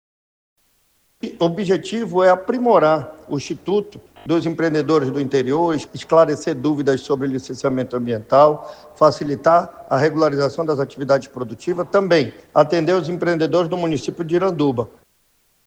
O diretor-presidente do Ipaam, Gustavo Picanço, destaca que a iniciativa busca disponibilizar orientação técnica e facilitar o acesso aos serviços ambientais oferecidos pelo órgão, tanto em Manacapuru, como em Iranduba.